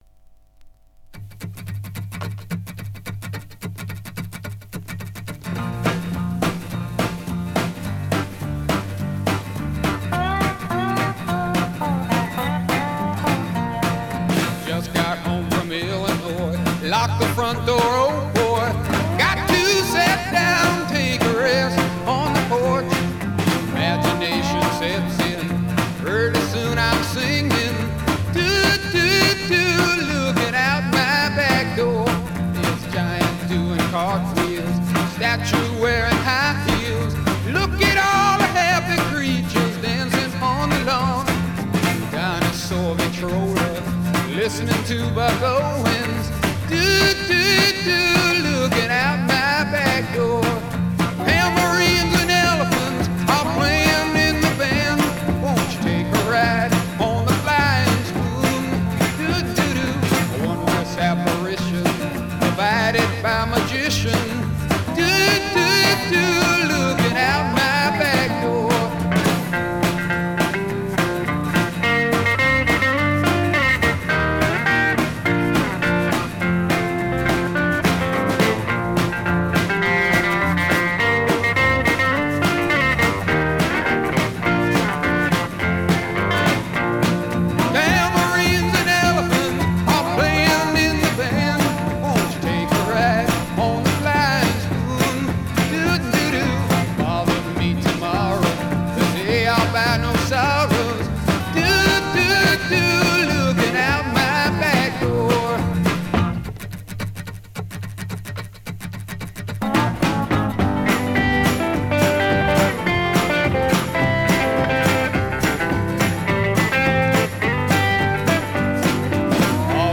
Жанр: Rock
Стиль: Classic Rock, Country Rock